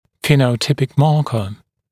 [ˌfiːnə(u)’tɪpɪk ‘mɑːkə][ˌфи:но(у)’типик ‘ма:кэ]фенотипический маркер